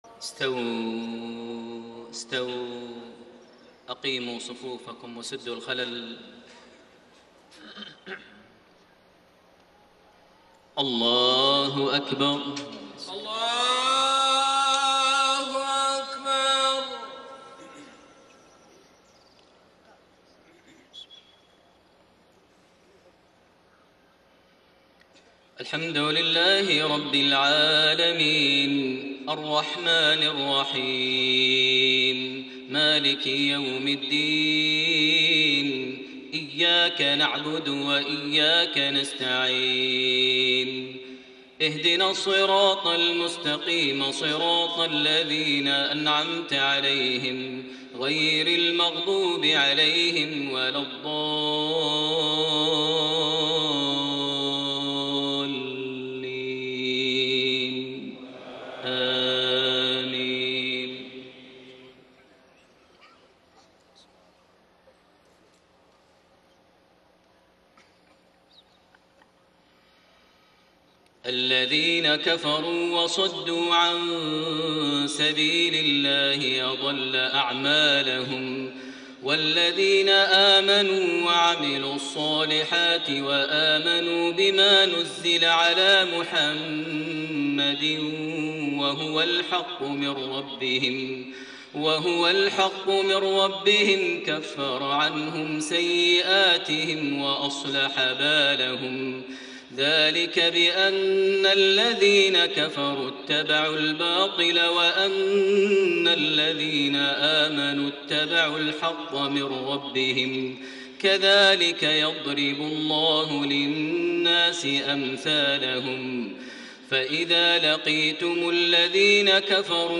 صلاة المغرب 16 ربيع الثاني 1433هـ فواتح سورة محمد1-12 > 1433 هـ > الفروض - تلاوات ماهر المعيقلي